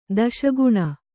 ♪ daśa guṇa